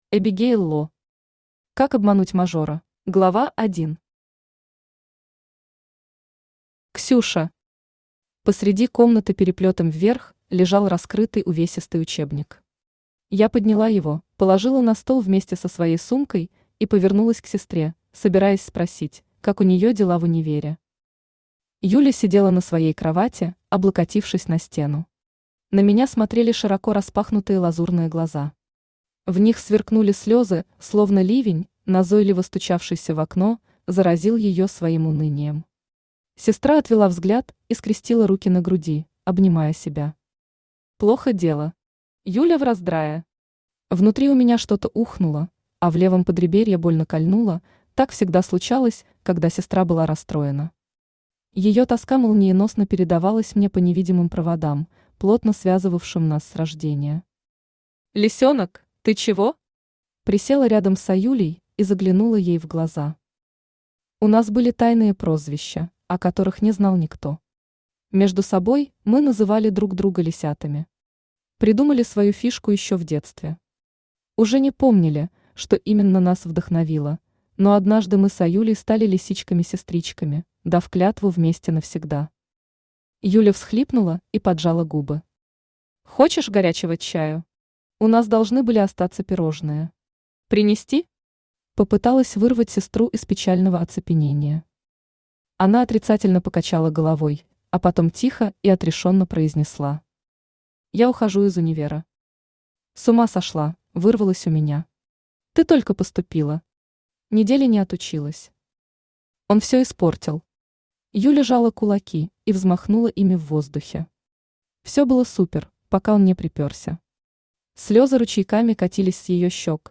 Аудиокнига Как обмануть мажора?
Автор Эбигейл Ло Читает аудиокнигу Авточтец ЛитРес.